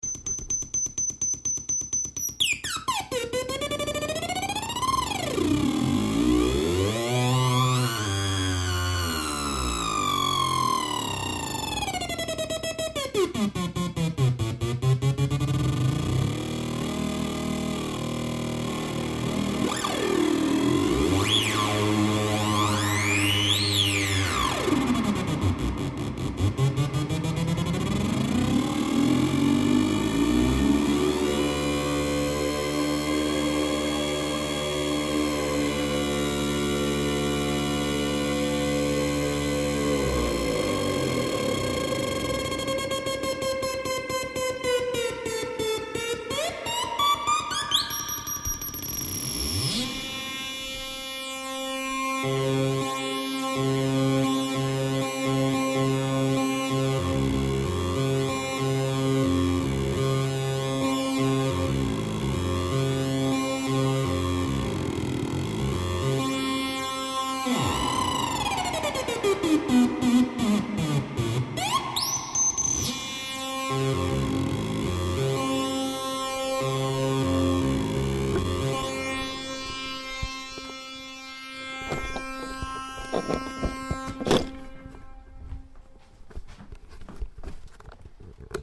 1st test with NAND Gate Synth